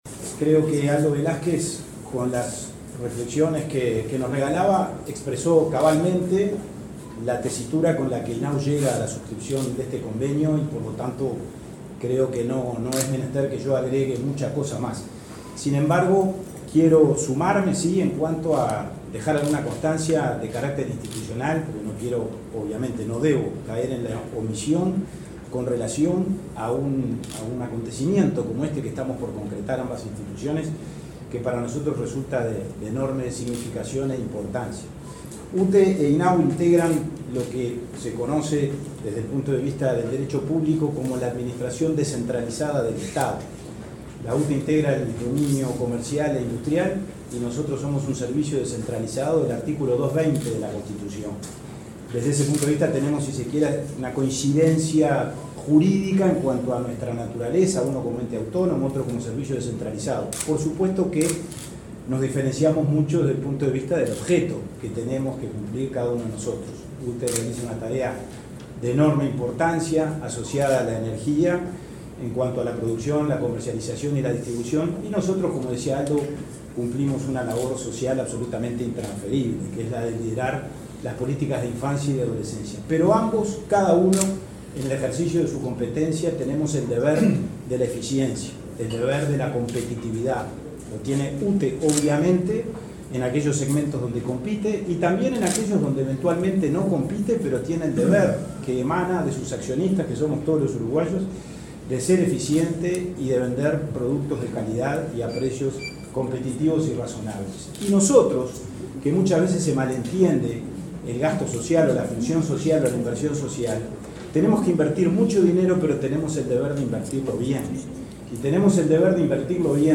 Palabras de autoridades en convenio entre UTE e INAU